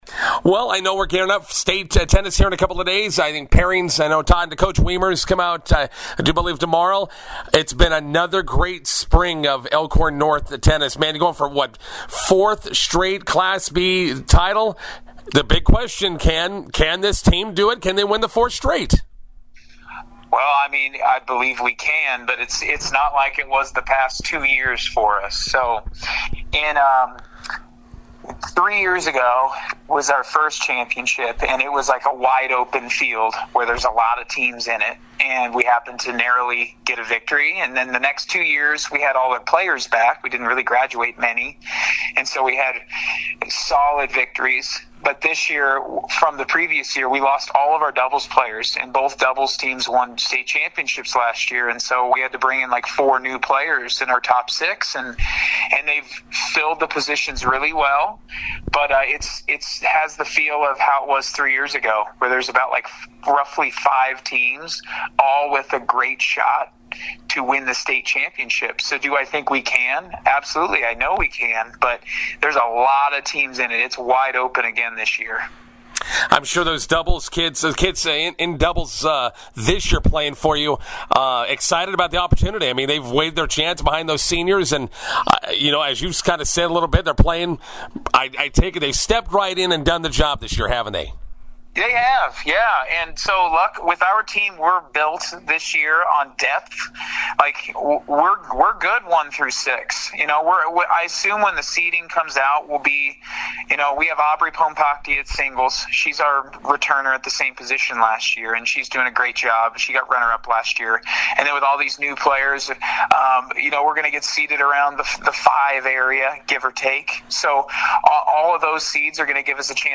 INTERVIEW: Elkhorn North shooting for their fourth straight Class B state tennis title.